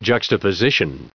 added pronounciation and merriam webster audio